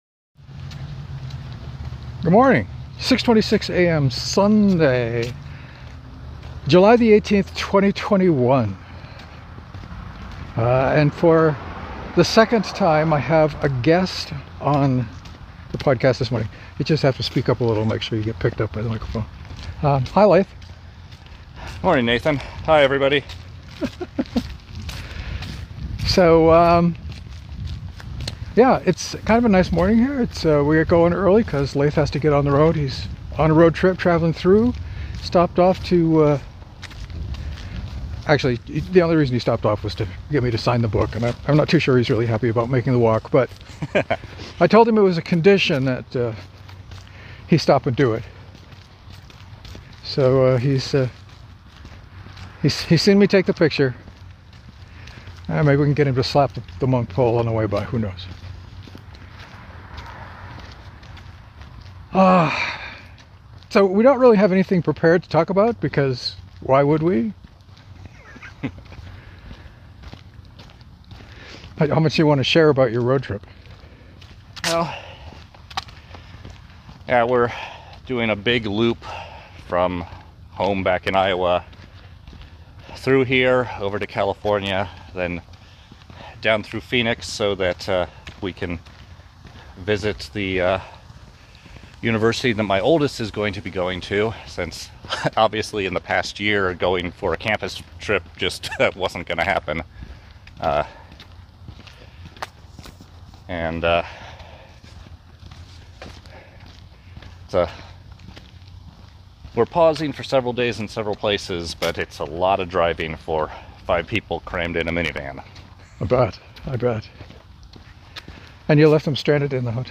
I’m not sure how well the audio pickup worked, but here’s our conversation.